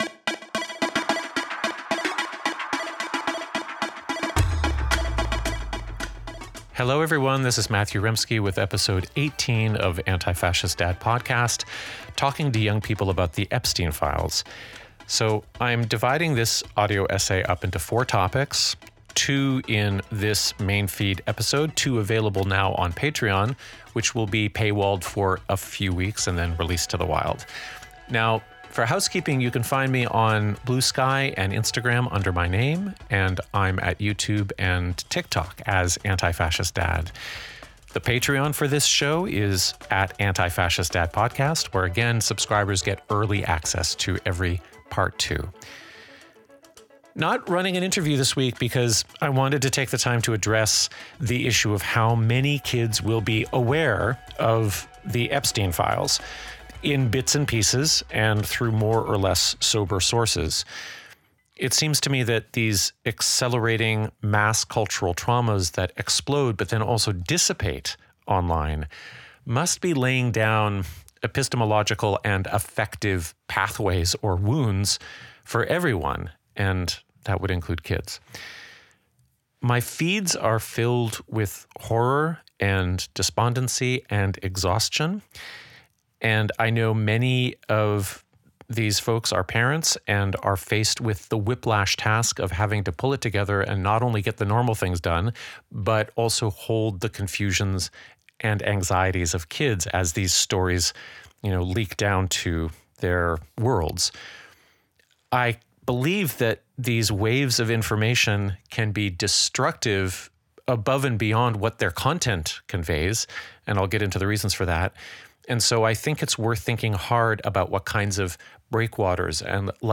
An audio essay on how to talk with young people about the Epstein files from an antifascist perspective. How to hold questions without amplifying sensationalism. How to name harms amidst the absence of accountability.